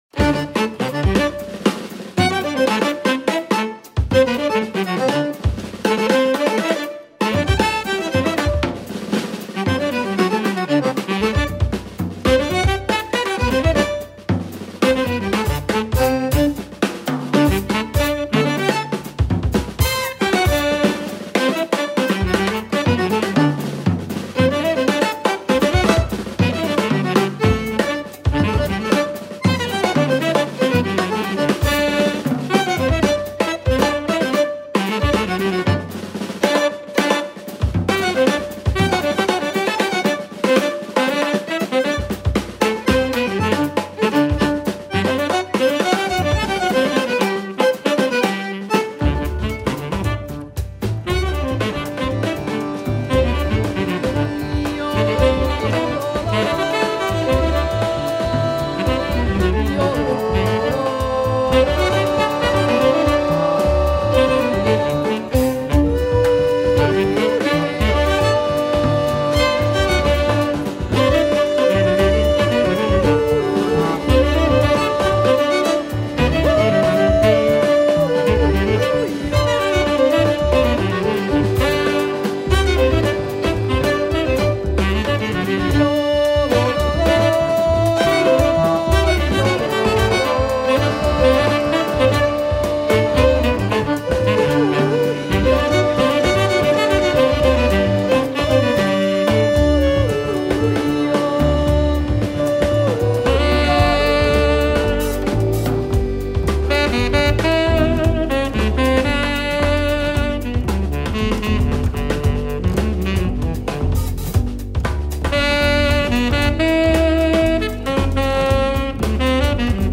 Folk / jazz.
sextet
violinist
accordionist
who give the music a touch of satin.